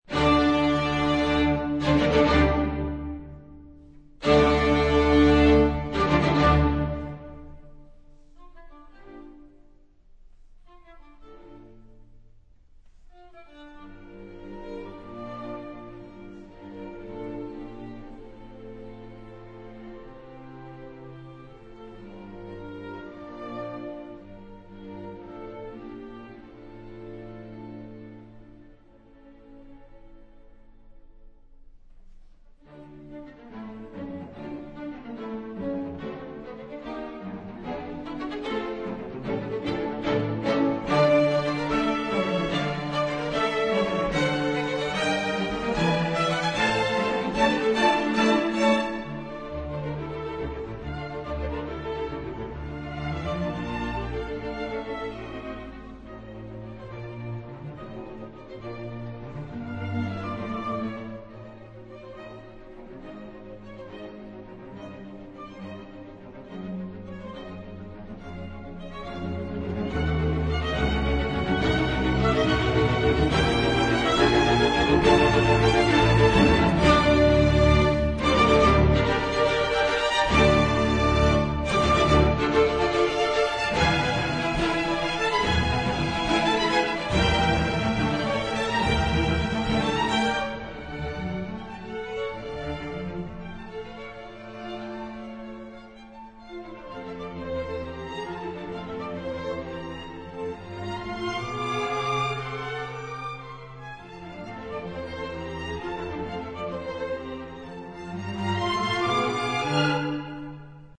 在片子的第一軌（試聽一），用了一個活潑、民俗性格的開場。